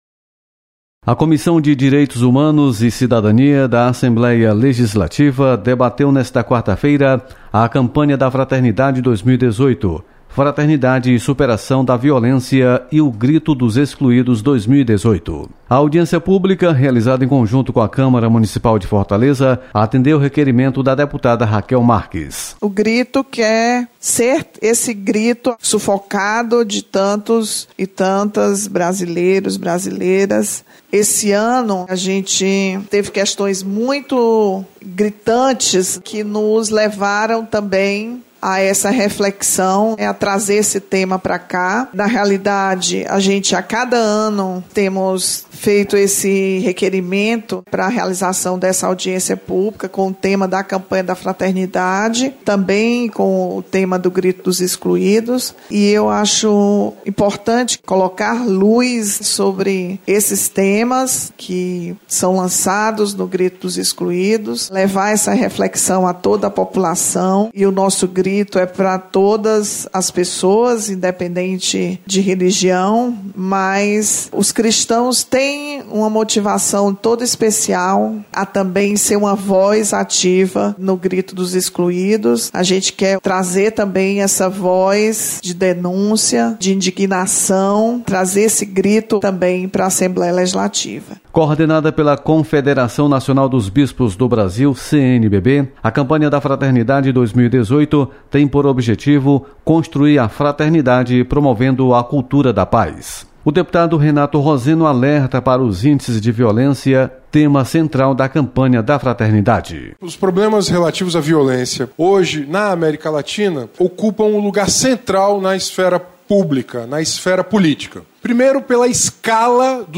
Audiência